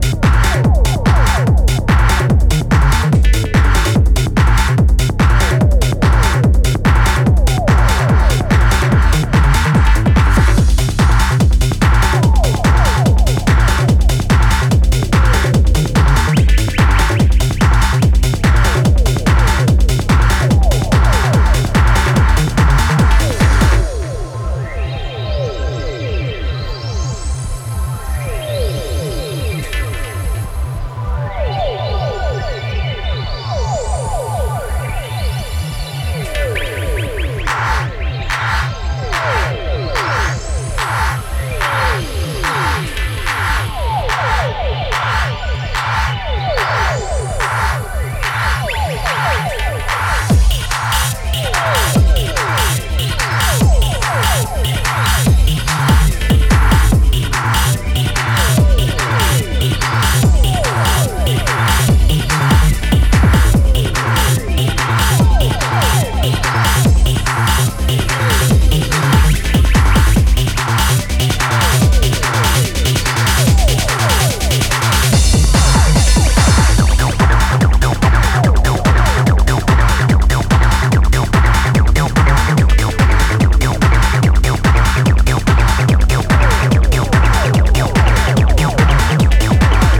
Techno Acid